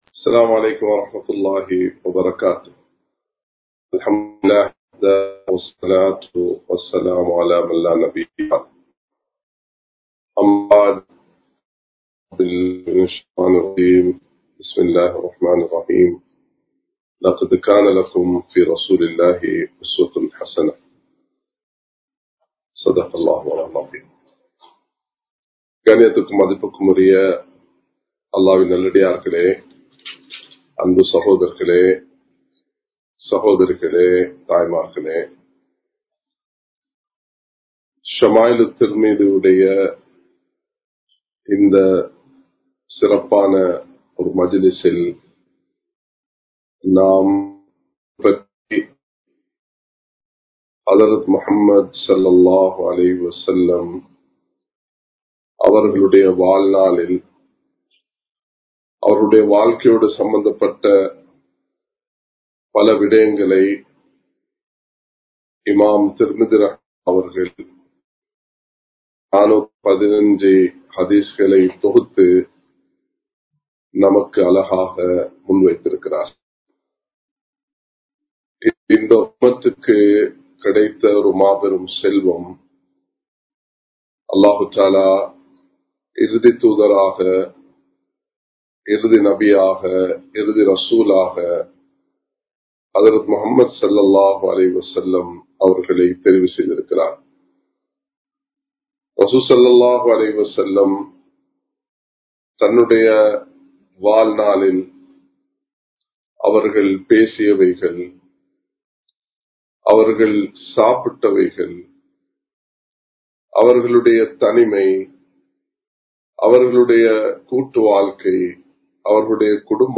நபி (ஸல்) அவர்களின் ஆடை அமைப்பு | Audio Bayans | All Ceylon Muslim Youth Community | Addalaichenai
Live Stream